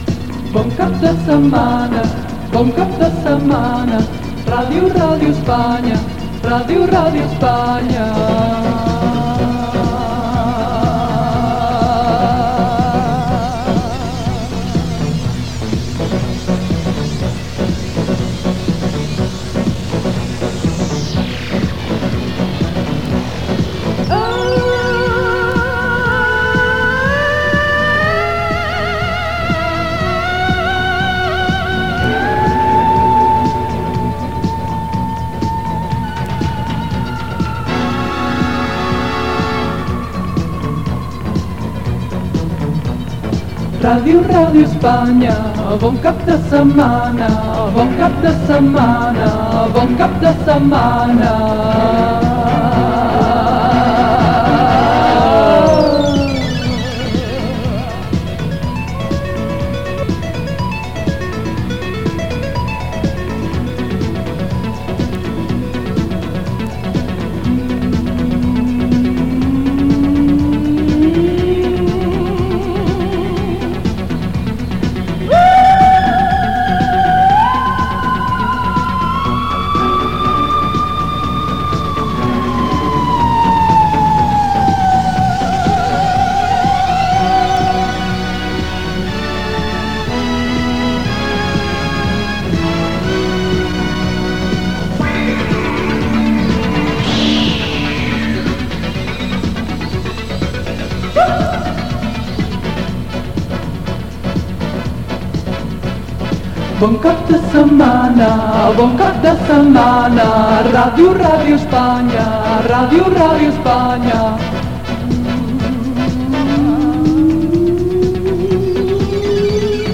Jingle llarg cantat del programa